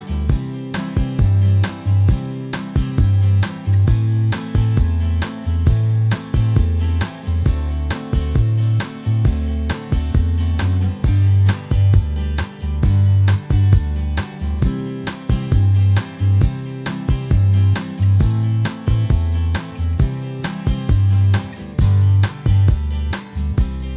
Minus Acoustic Guitars Soft Rock 6:19 Buy £1.50